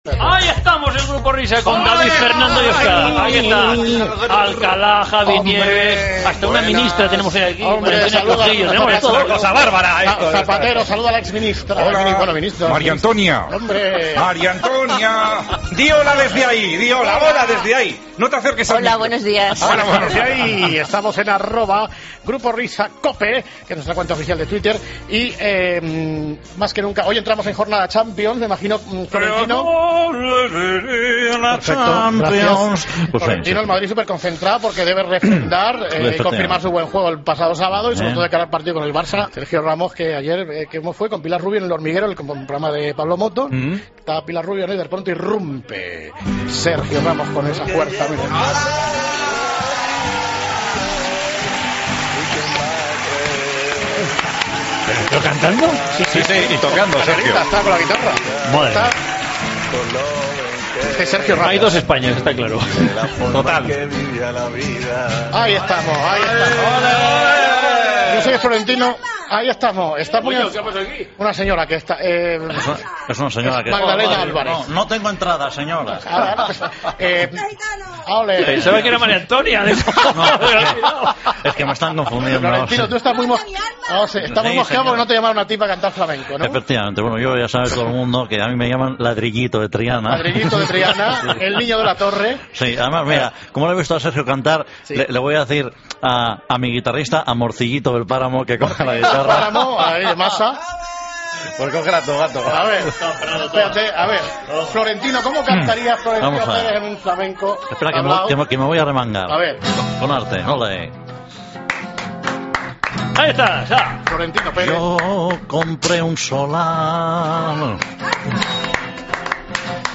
AUDIO: Después de Sergio Ramos, Florentino, Butragueño y Montoro se arrancan con la guitarra